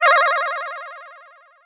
Sonic 1 Teleport